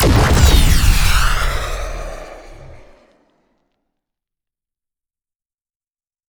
missilef.wav